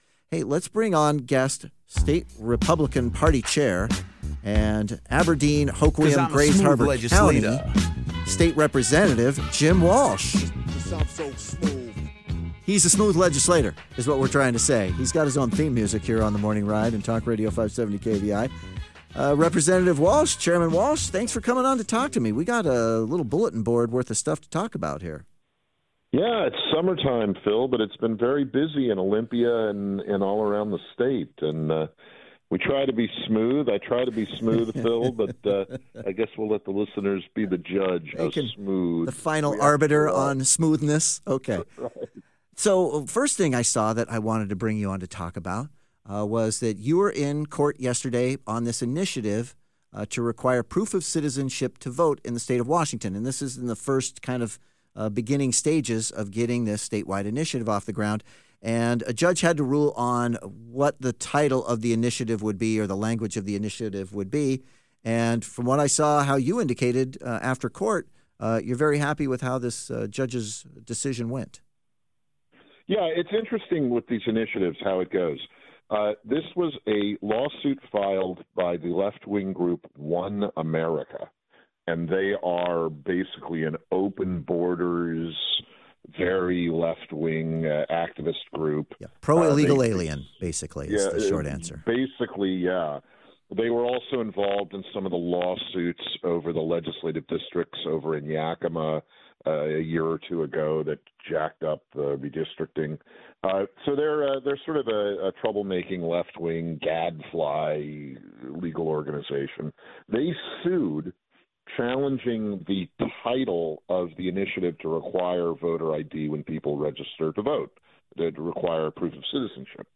WAGOP Chairman and State Rep. Jim Walsh joins The Morning Ride to give the latest updates on initiative IL26-126 which, if passed, will require voter ID in Washington state.